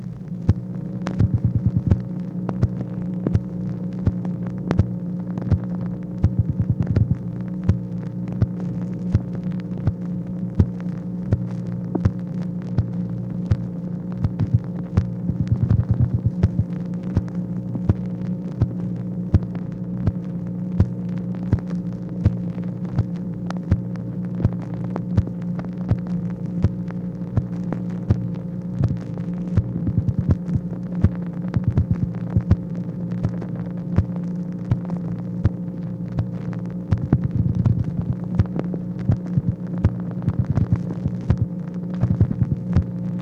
MACHINE NOISE, July 20, 1964
Secret White House Tapes